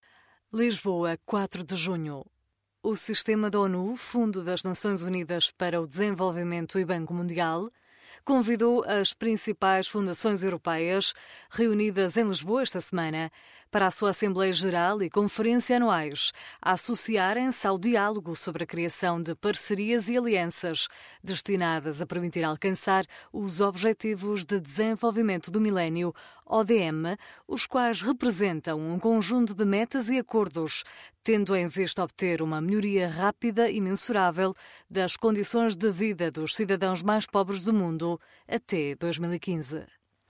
Portuguese European Female Voice
Sprechprobe: eLearning (Muttersprache):